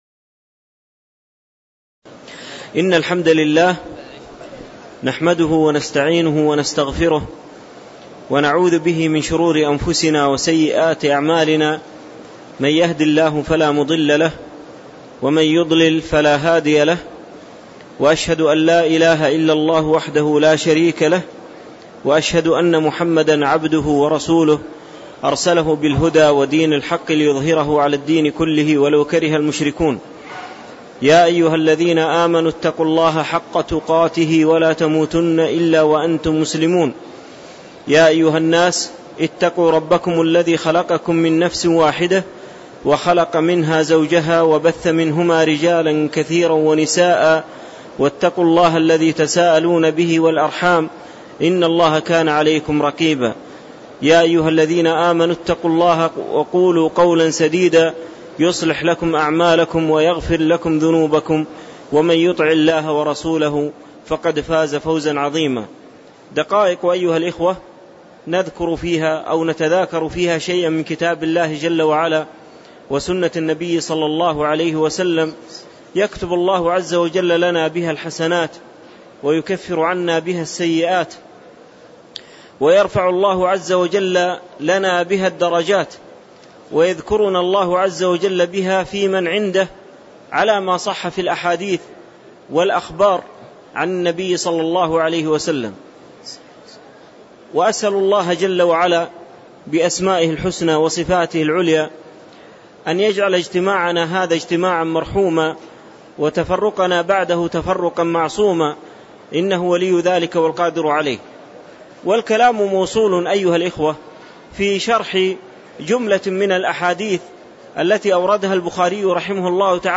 تاريخ النشر ١٩ ذو القعدة ١٤٣٧ هـ المكان: المسجد النبوي الشيخ